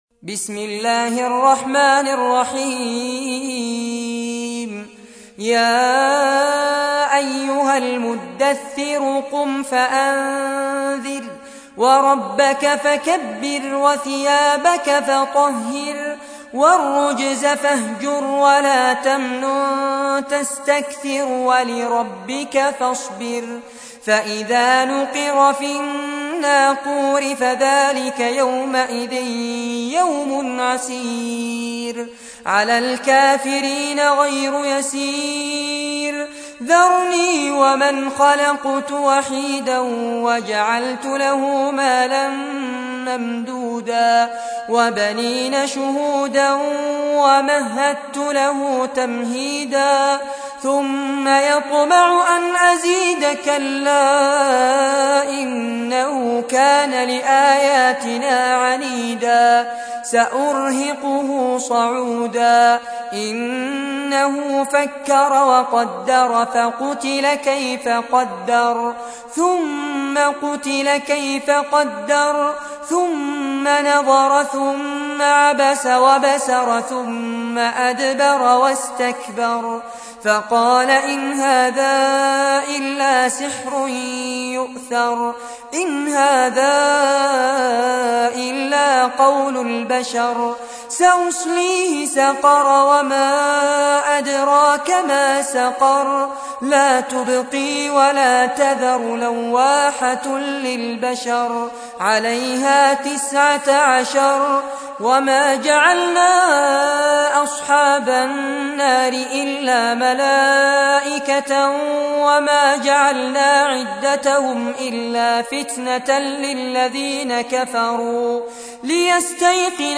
تحميل : 74. سورة المدثر / القارئ فارس عباد / القرآن الكريم / موقع يا حسين